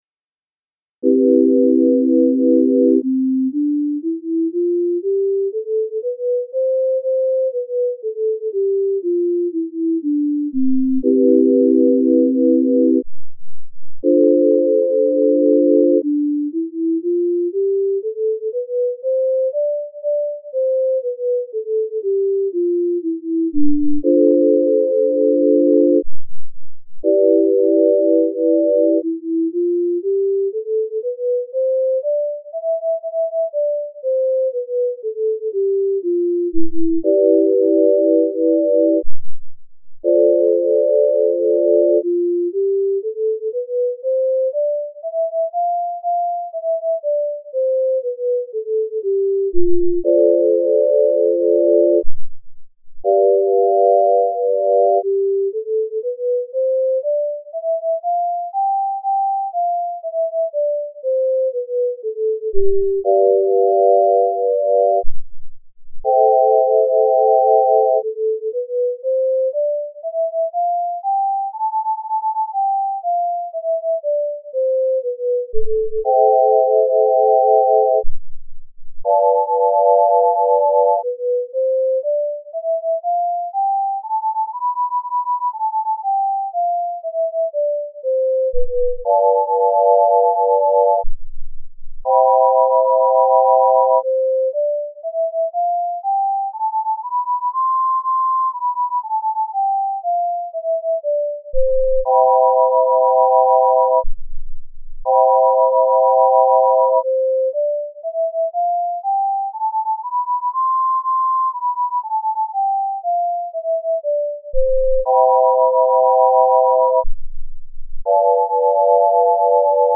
C-Major Scale Using the Tempered Left Ear and Just Right Ear Scale